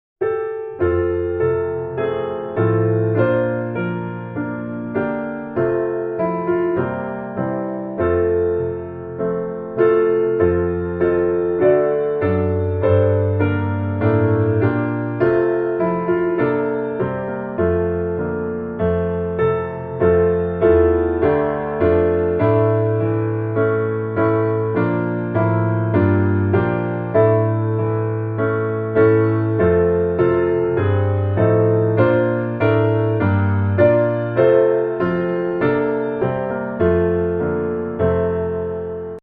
Hymn: Feed on His faithfulness, my soul
F Major